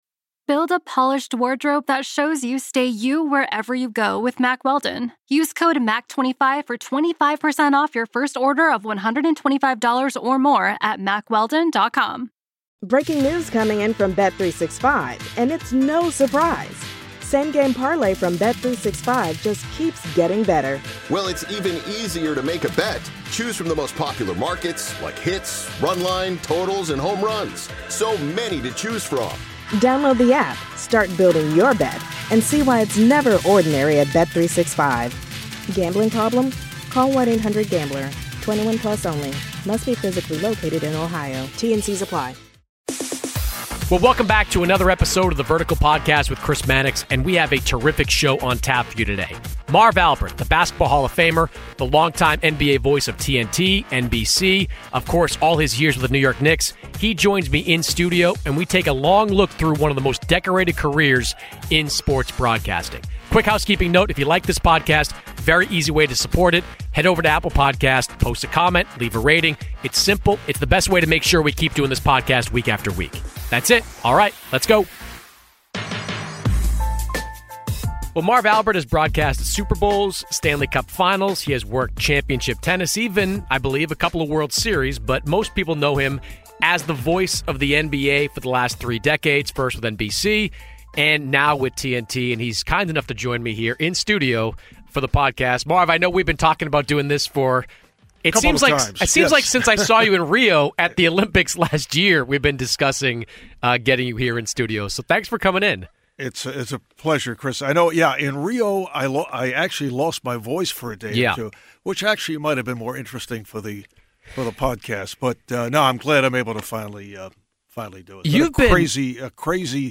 Legendary broadcaster Marv Albert The Crossover NBA Show SI NBA Basketball, Sports 4.6 • 641 Ratings 🗓 27 June 2017 ⏱ 57 minutes 🔗 Recording | iTunes | RSS 🧾 Download transcript Summary Joining Chris Mannix on The Vertical this week is the legendary broadcaster Marv Albert. Chris talks with Marv about his broadcasting career and what it was like covering the biggest and best athletes around the world.